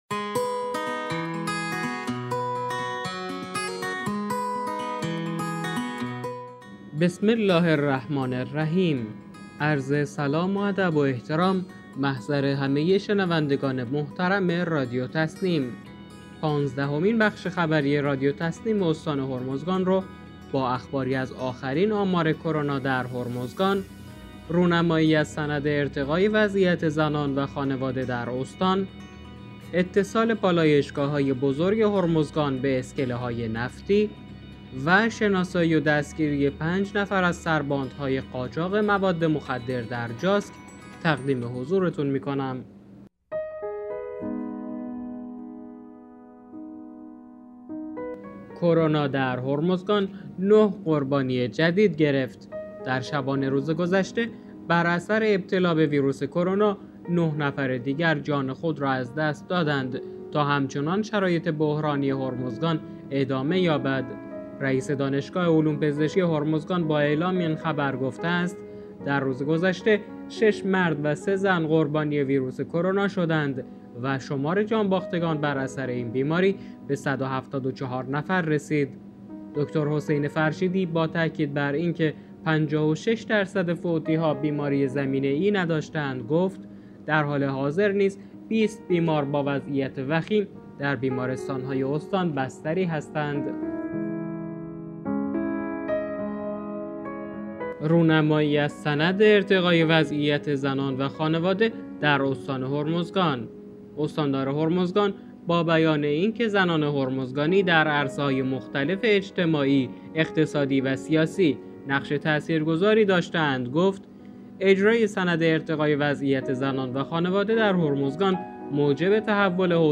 به گزارش خبرگزاری تسنیم از بندرعباس، پانزدهمین بخش خبری رادیو تسنیم استان هرمزگان با اخباری از آخرین آمار کرونا در هرمزگان، رونمایی از سند ارتقای وضعیت زنان و خانواده در استان، اتصال پالایشگاه‌های بزرگ هرمزگان به اسکله‌های نفتی و شناسایی و دستگیری 5 نفر از سرباندهای قاچاق مواد مخدر در جاسک منتشر شد.